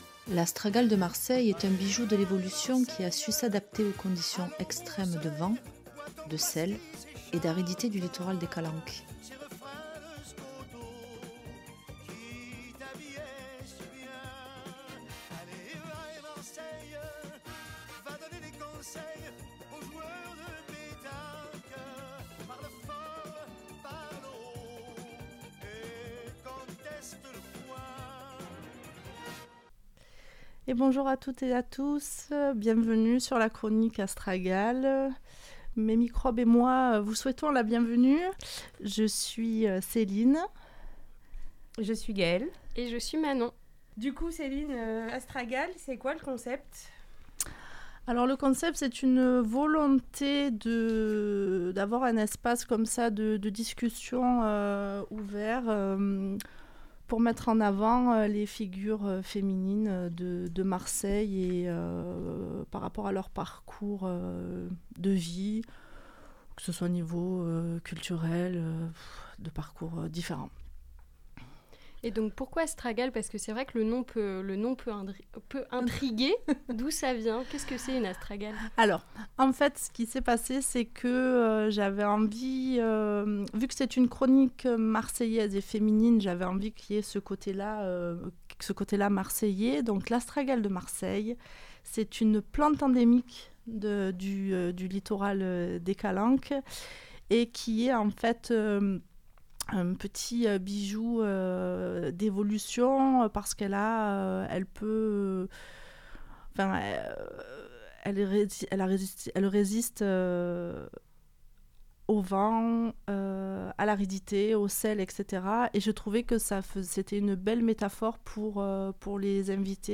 Astragales, épisode n°1 Mercredi 19 Mars 2025 "Astragales" est une chronique mensuelle qui s'inscrit dans une volonté de valorisation de la résilience féminine et de parcours de vie des femmes qui « font » Marseille. Le projet vise à donner la parole aux femmes marseillaises aux parcours de vie variés (socialement, culturellement, professionnellement). Ces voix donneront à l'audience un aperçu des défis spécifiques qu'elles ont pu rencontrer, avec un accent sur leur rapport à la musique et à la cité phocéenne.